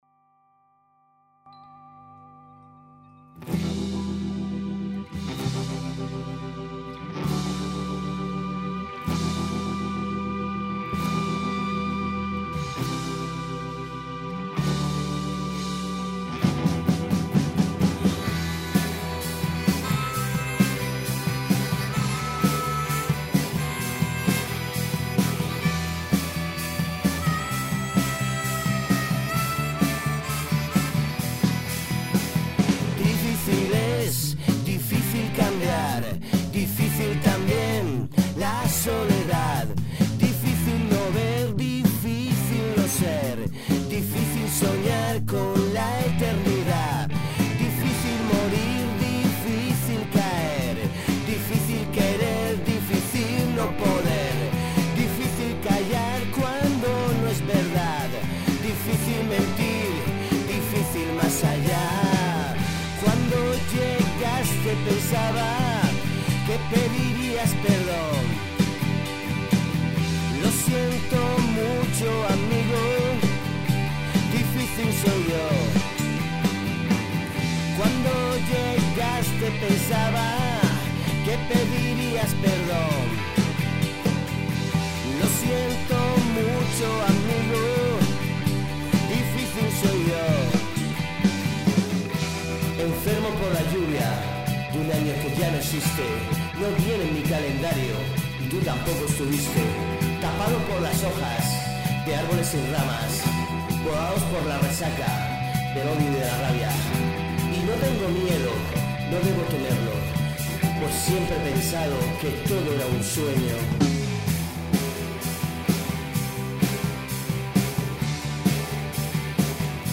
pop-rock
Guitarra, armónica, voz
Bajo
Bateria
Demo Songs